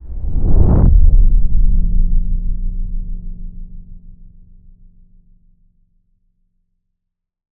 cinematic_deep_bass_pass_whoosh_02.wav